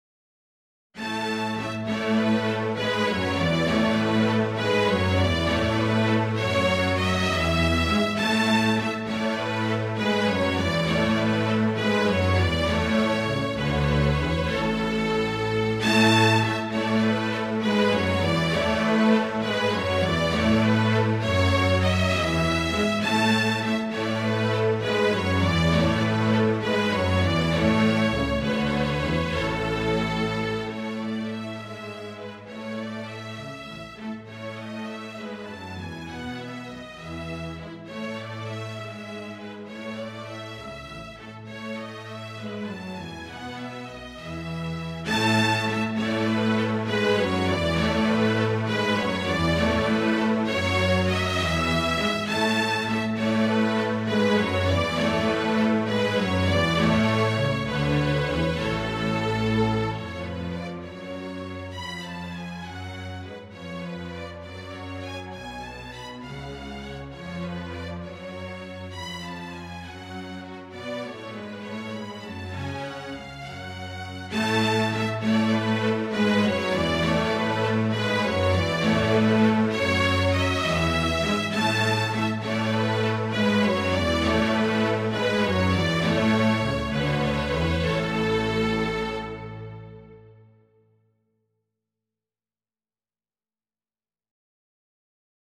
A scenario was published in the Mercure de France (June 1751: 2/160-66), and parts from the score (vln I, vln II and basso), written by Robert des Brosses (1719-1799), were also published.
The recordings are MIDI mockups, computer-generated using the playback software NotePerformer and performed as the music is written.
(MIDI mockup of no. 1, Entrée en Rondeau)